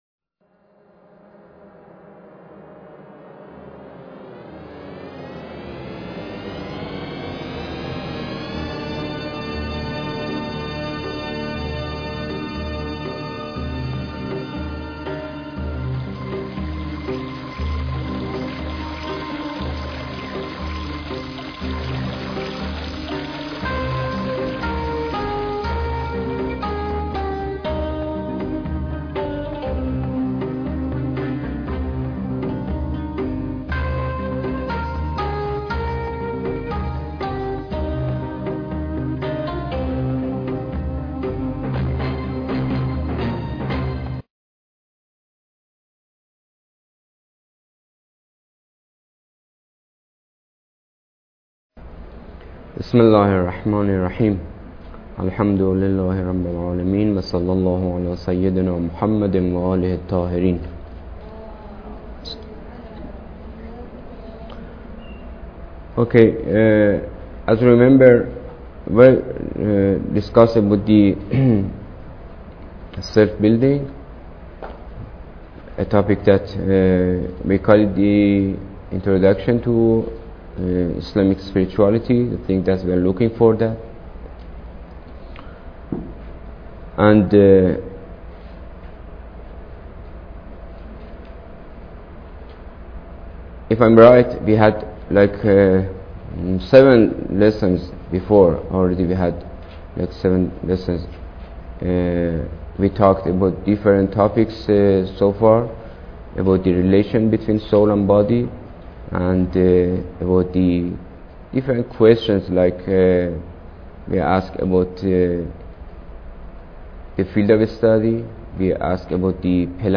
Lecture_8